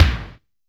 B.B KICK12.wav